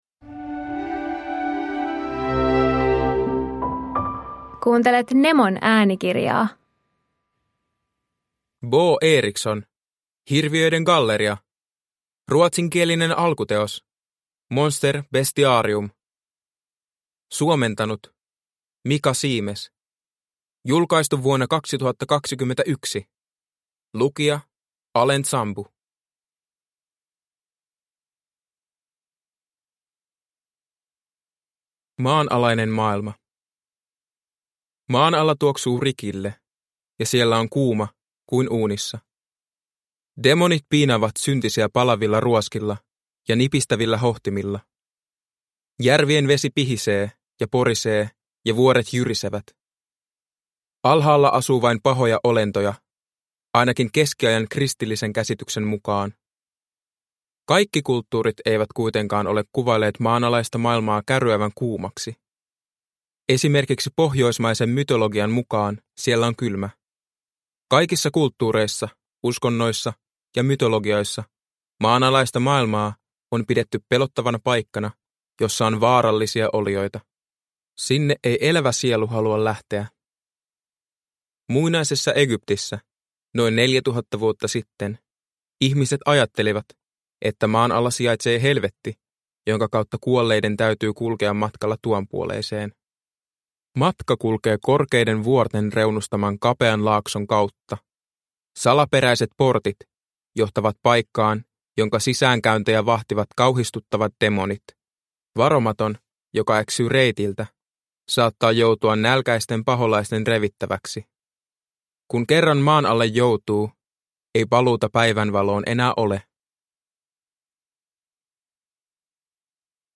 Hirviöiden galleria – Ljudbok – Laddas ner